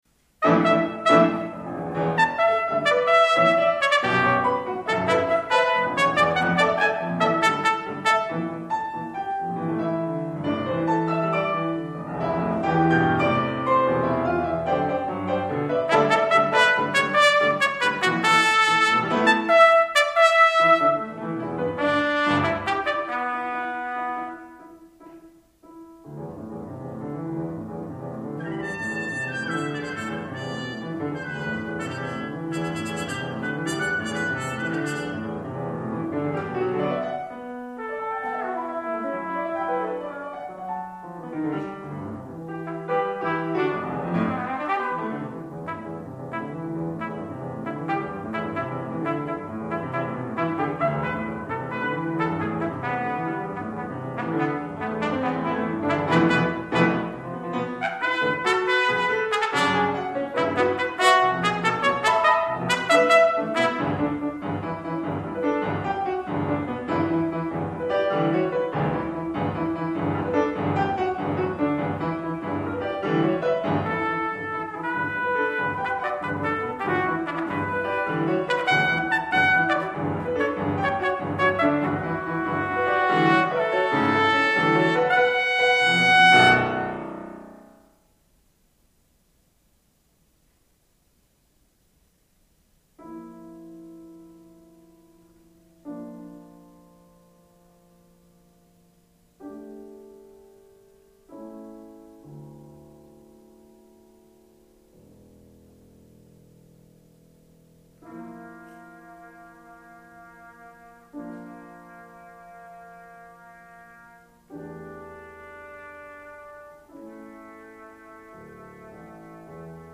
Venture (trumpet and piano
at Arizona State University’s Katzin Concert Hall